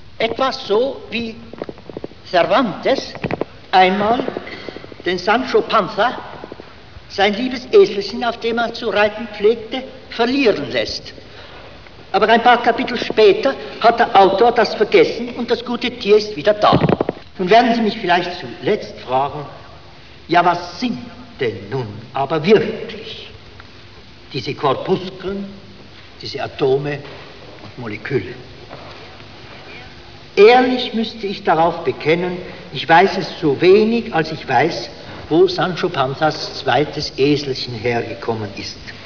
Erwin Schrödinger, 1952
Audio-document from the lecture "Was ist Materie?"